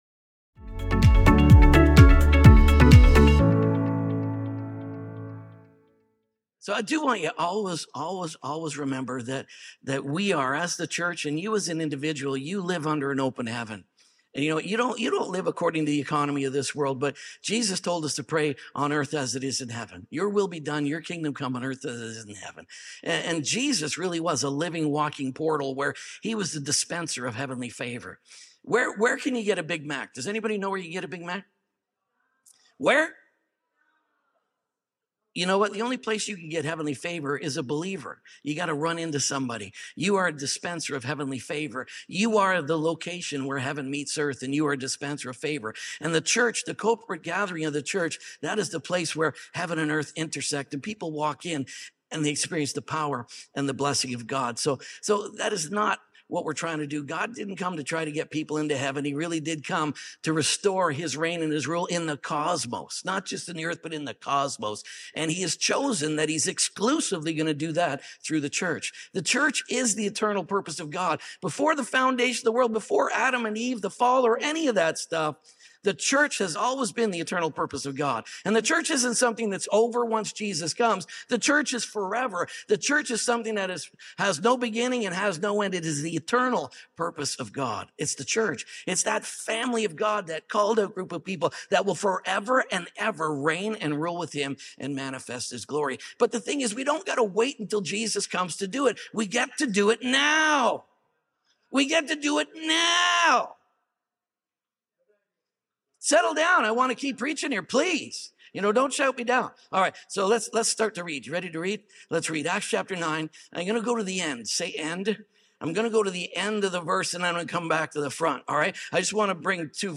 Terrorist To Apostle | Drench Series | SERMON ONLY.mp3